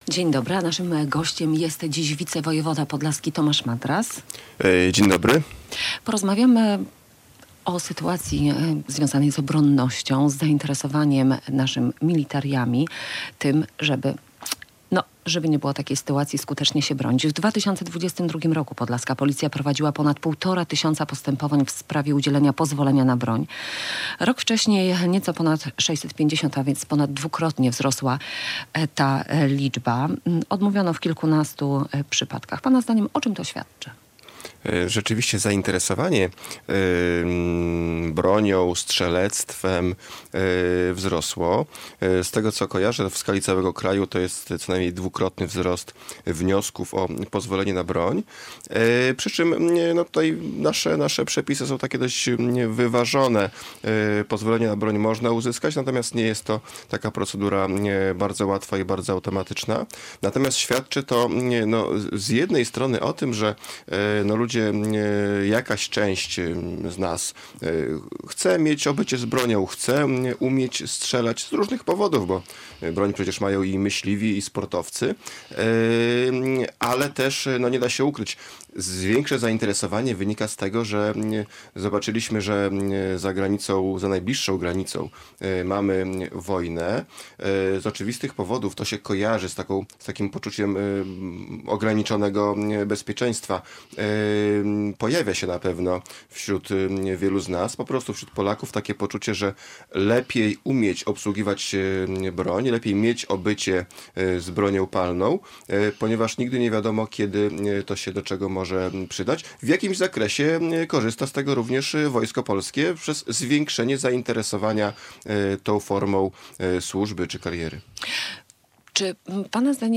Radio Białystok | Gość | Tomasz Madras [wideo] - wicewojewoda podlaski
wicewojewoda podlaski